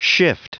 Prononciation du mot : shift
shift.wav